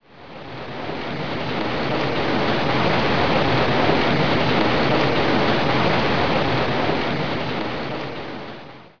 rain2_fade.wav